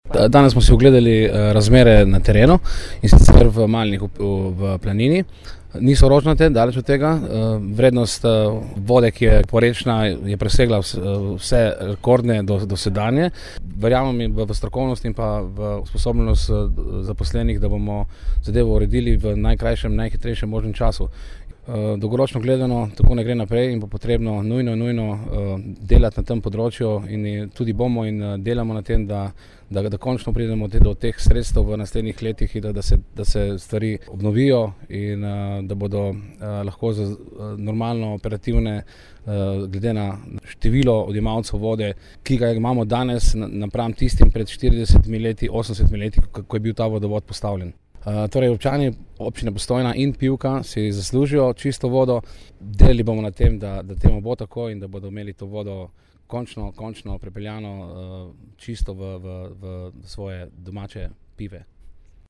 IZJAVA IGOR MARENTIČ